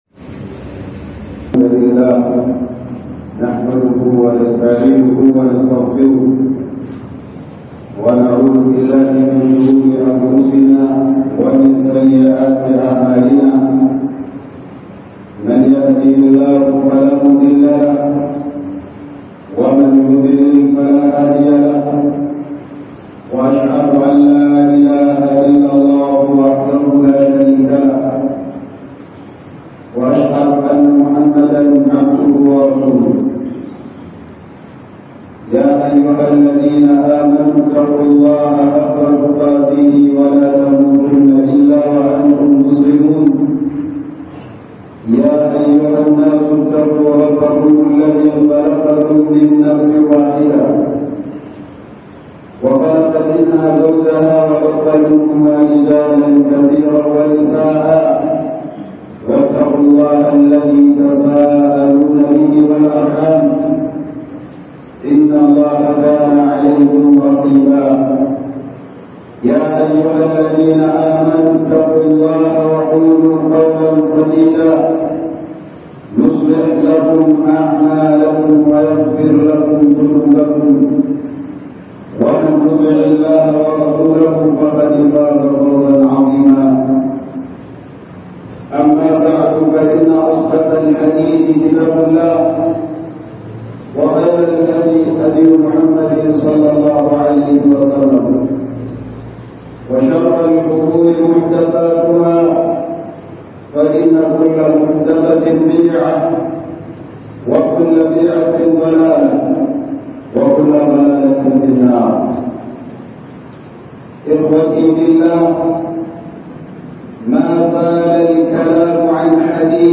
Taskoki masu albarka daga addu'o'in Manzon Allah sallahu alaihi wa sallam (3) - Huduba